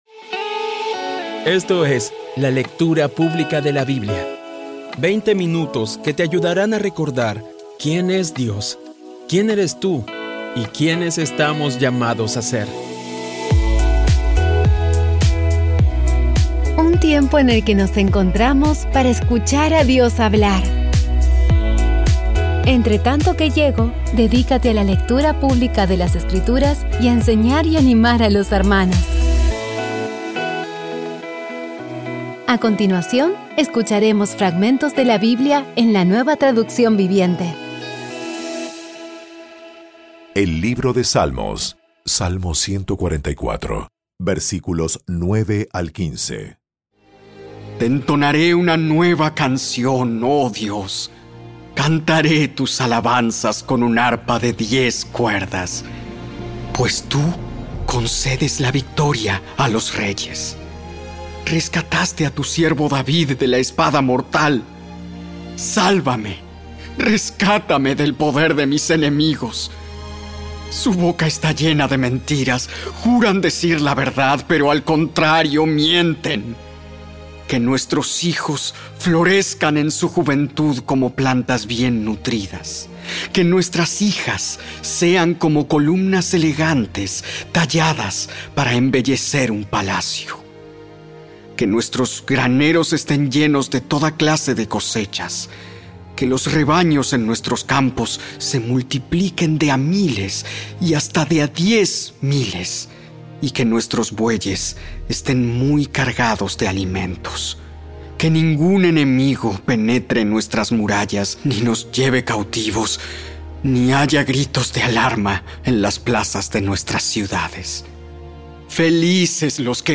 Audio Biblia Dramatizada Episodio 354
Poco a poco y con las maravillosas voces actuadas de los protagonistas vas degustando las palabras de esa guía que Dios nos dio.